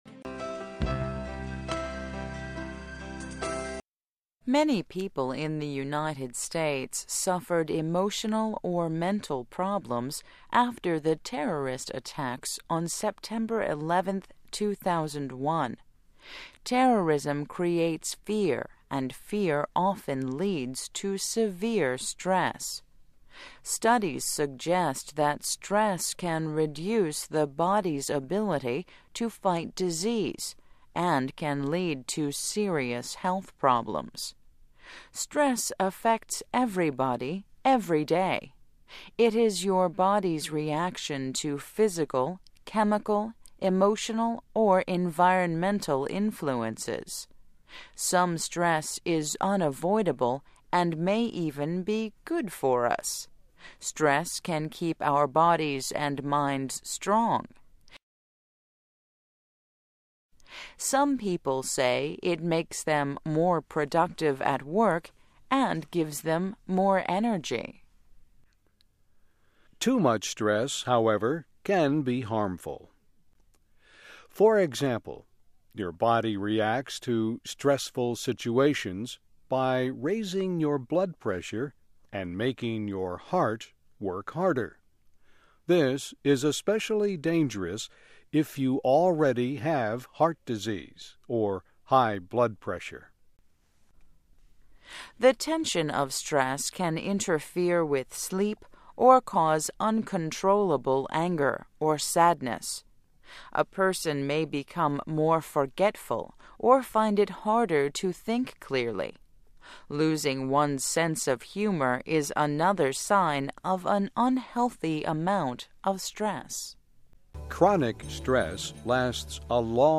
1. BÖLÜM  : DİNLEME-NOT ALMA
Bu bölümde öğrenciler bir konuşmayı iki defa dinleyip, notlarına dayanarak ilgili soruları cevaplarlar.